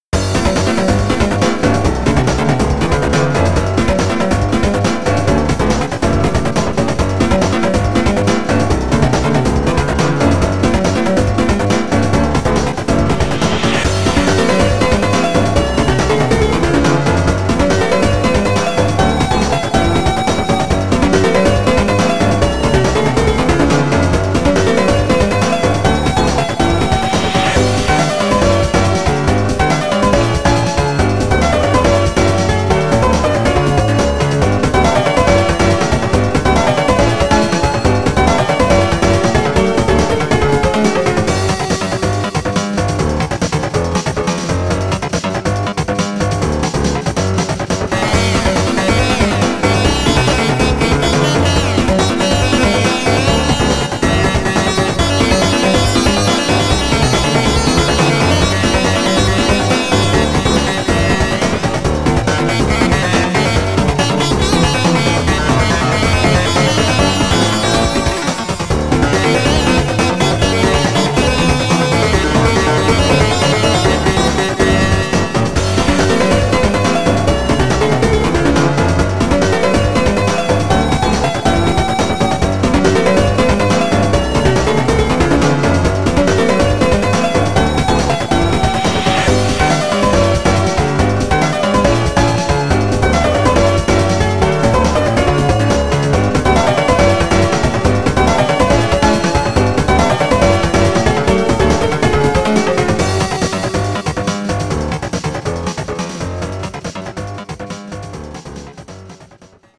BPM140
Comments[VIDEO GAME MUSIC]